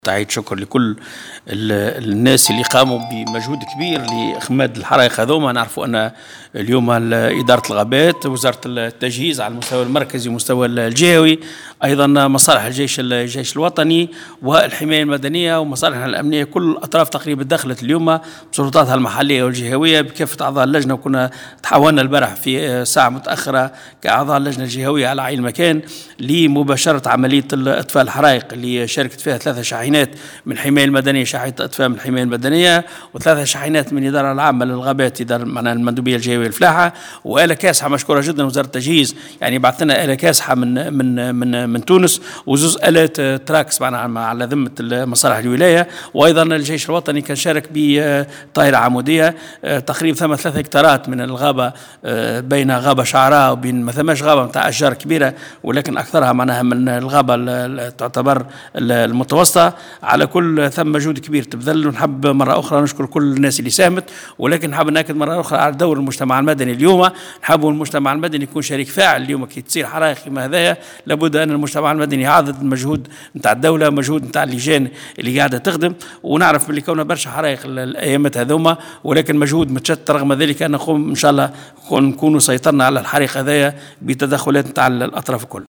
اكثر تفاصيل في تصريح  محمد قويدر